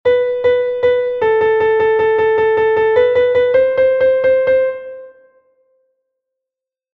Unha negra con barra e un 3; unha negra con dúas barras e un 6; dúas corcheas cunha barra e un 3 cada unha; unha negra con dúas barras e un 5